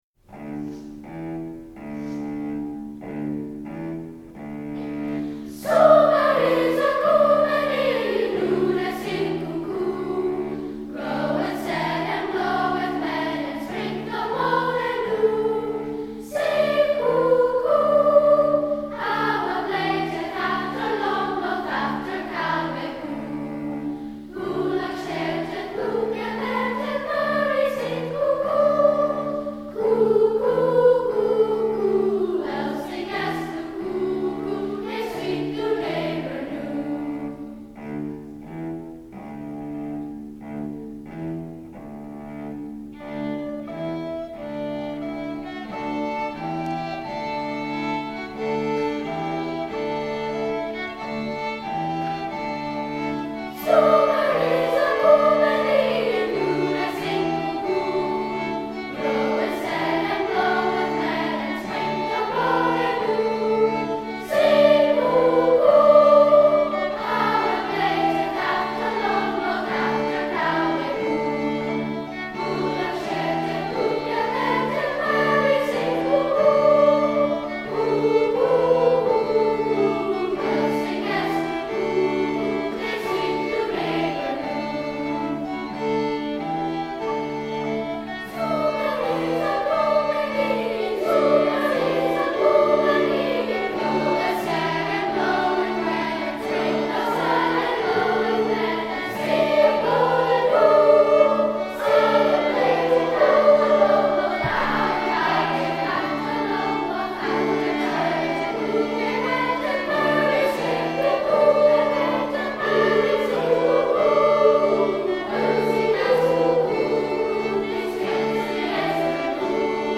It is a Medieval rota – the ancestor of the musical form that would be known later as a round – and is the oldest known example of six-part polyphony, and also one of the oldest surviving example of counterpoint.
Sumer Is Icumen In consists of a four-part canon sung over a two-part burden (or pes, as it is called in the original score).
All the instruments on the recording are played by students as well. The entire choir sings the song in unison twice: first over a simple ostinato on cello, then over the burden played in two parts by recorders and strings.  The burden continues through the rest of the arrangement, and the choir then sings the canon in two parts, and finally in four.